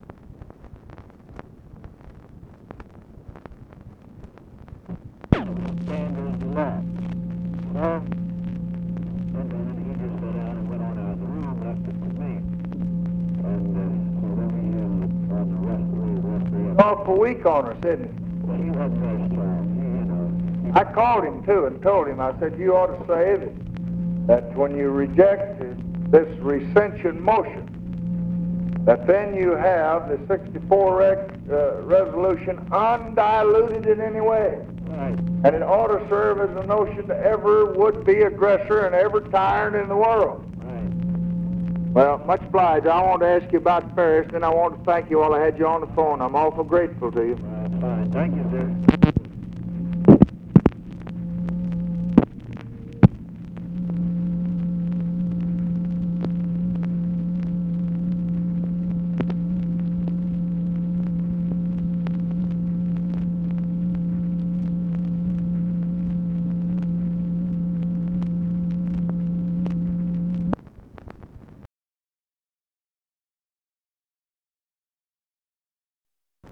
Conversation with GEORGE SMATHERS, March 2, 1966
Secret White House Tapes